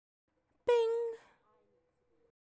钥匙的叮当声
描述：这是键的叮当声。
Tag: 钥匙圈 顺口溜